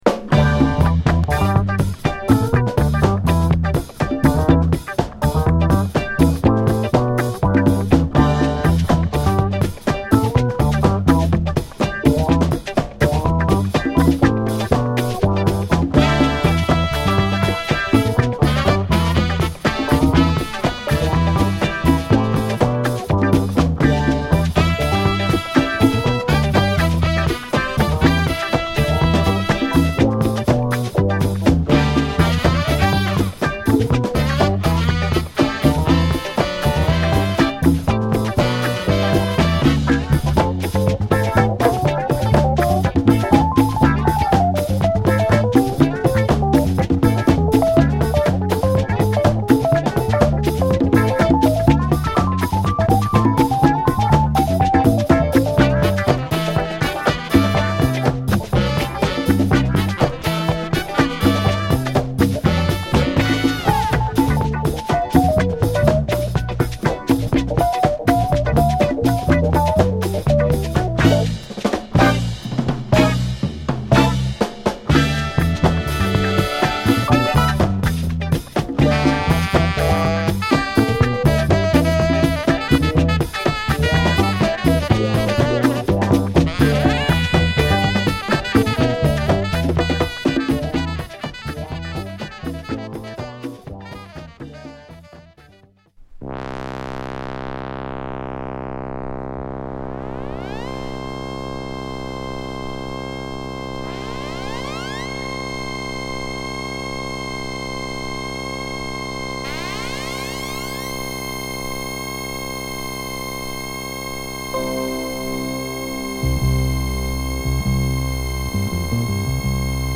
いつもよりもソウル〜レアグルーヴ感の強い1枚です！